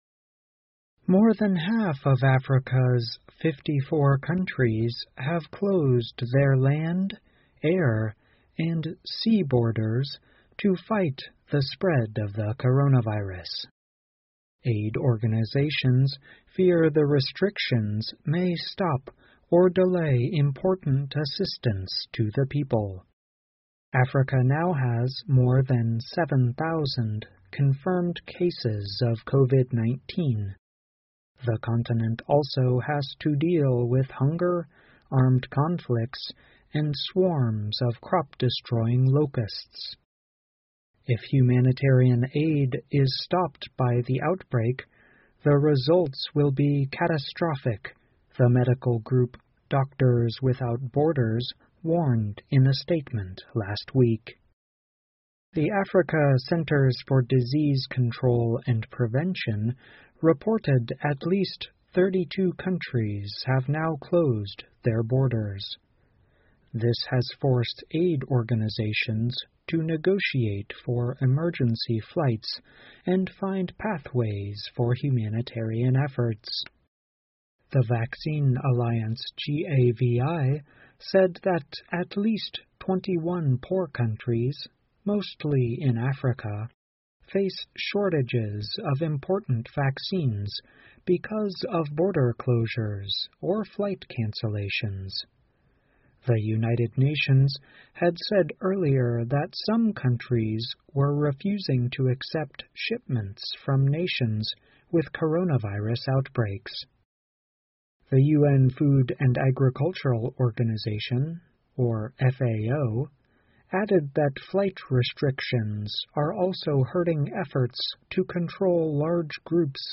VOA慢速英语2020 非洲封锁导致物资运送受阻 听力文件下载—在线英语听力室